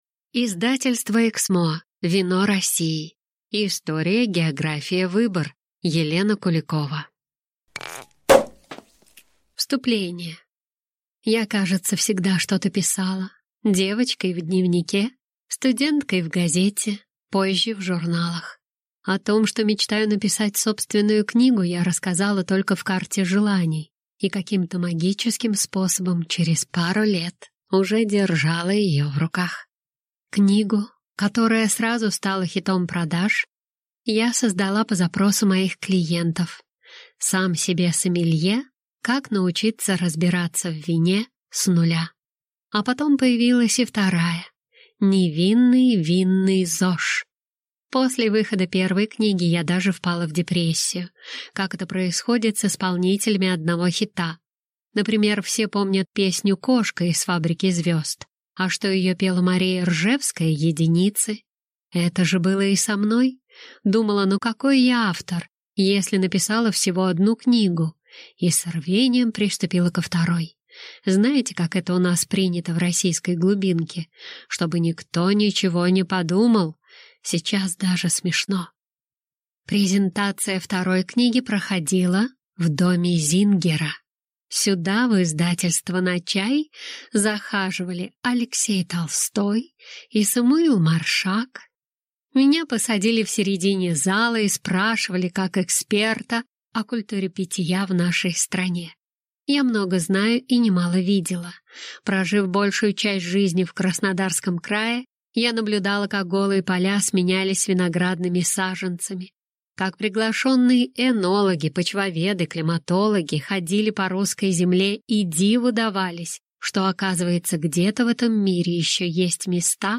Аудиокнига Вино России. История, география, выбор | Библиотека аудиокниг